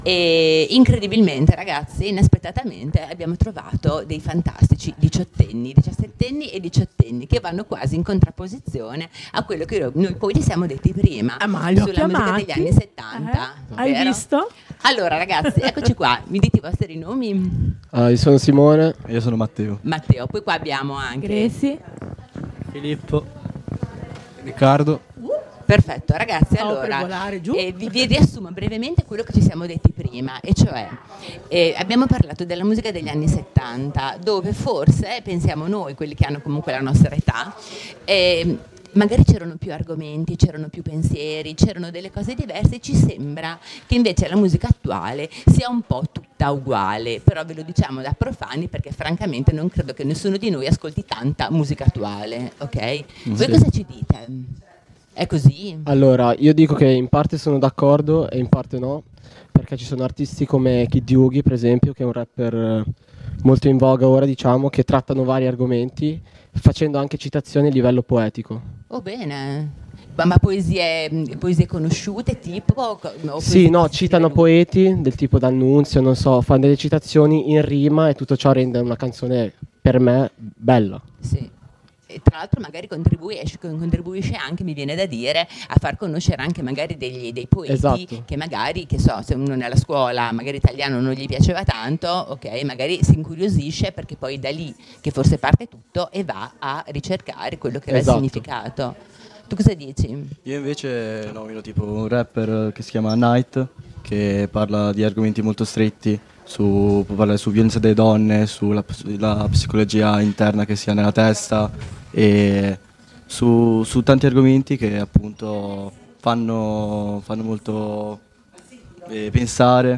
Le dirette di Linea Radio al Clhub di viale XX Settembre a Sassuolo